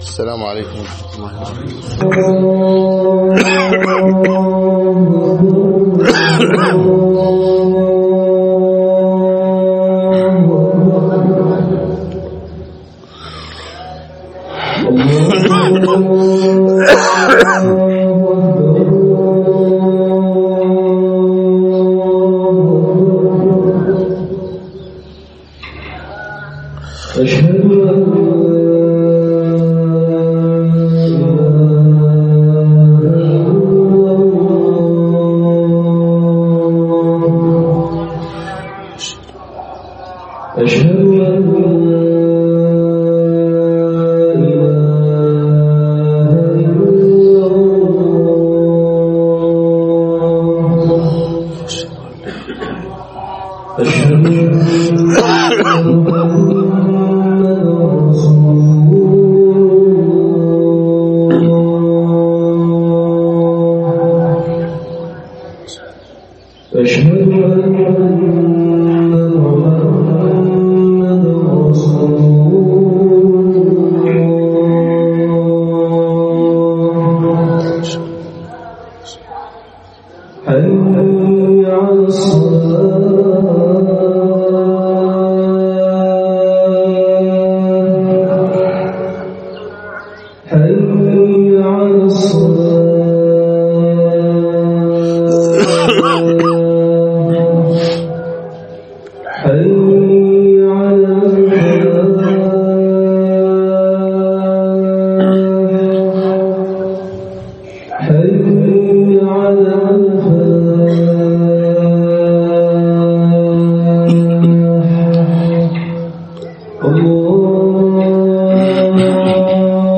خطب صوتية